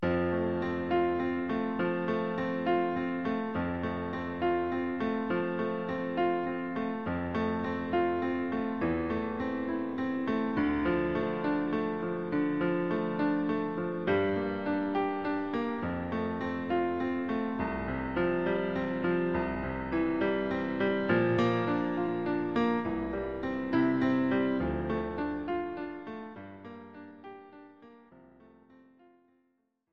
This is an instrumental backing track cover.
• Key – F
• Without Backing Vocals
• No Fade
Backing Track without Backing Vocals.